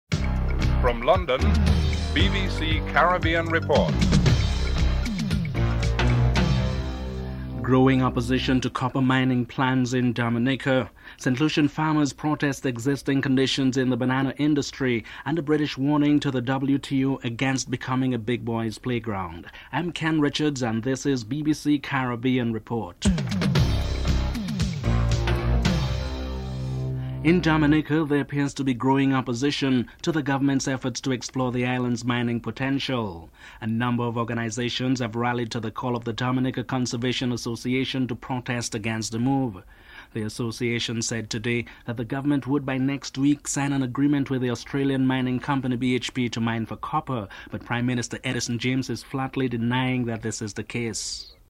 1. Headlines (00:00-00:27)
5. There were signs of anguish and disappointment in Trinidad and Tobago today where twenty Guyana law students were told that their degrees would not give them access to final studies. A student is interviewed (09:13-11:06)